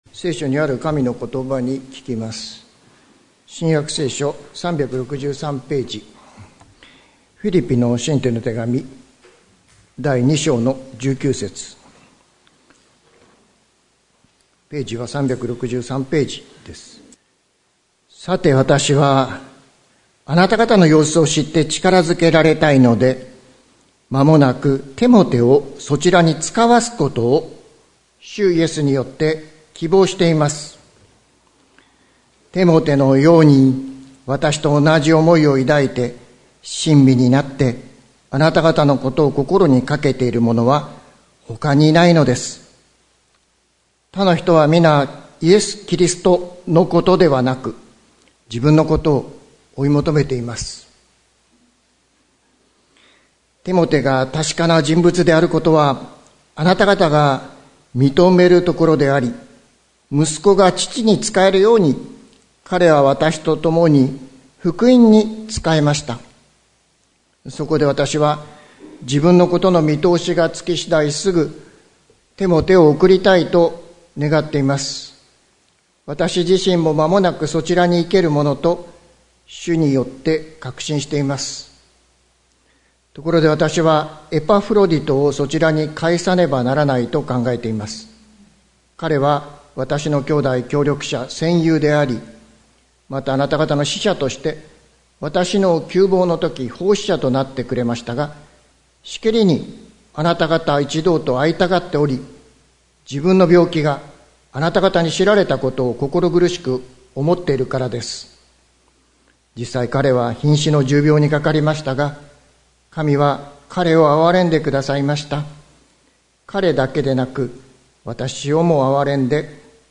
関キリスト教会。説教アーカイブ。